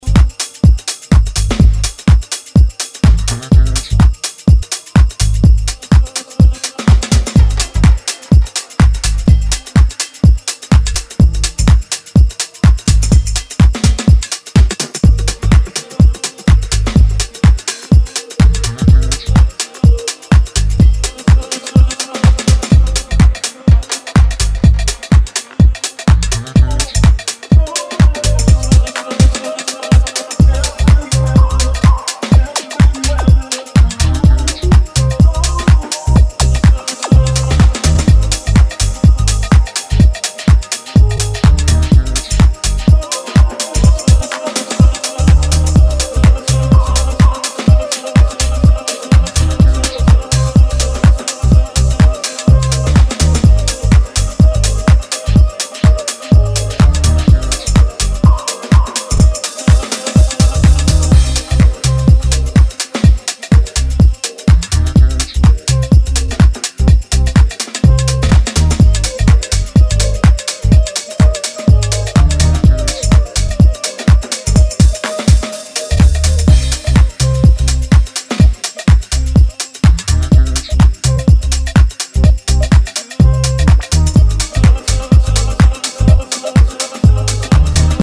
remixes. Classic house cuts